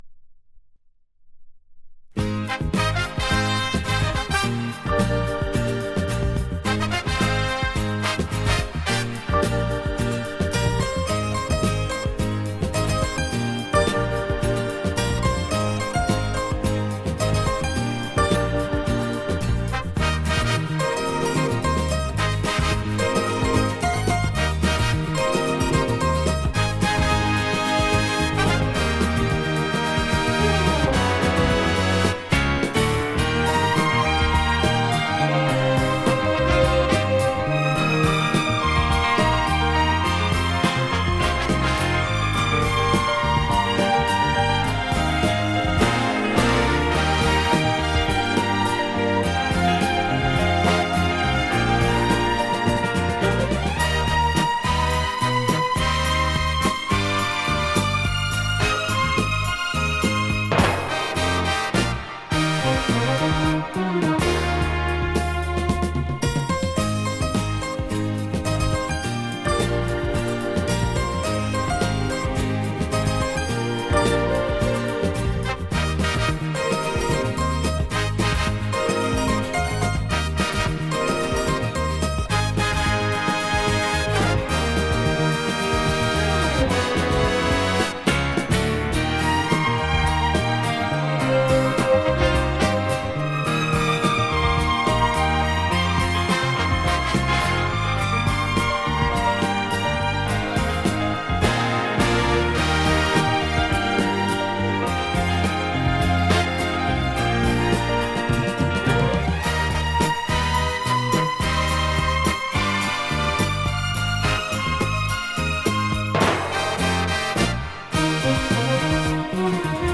Жанр: Easy Listening, New Classic